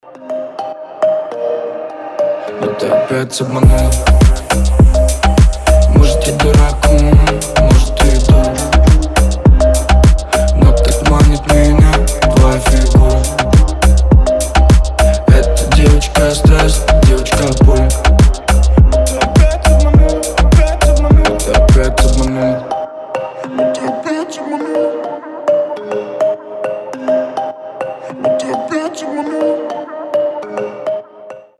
• Качество: 320, Stereo
русский рэп
мелодичные
ксилофон